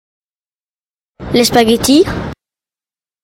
prononciation Spaghettis
spaghetti_mot.mp3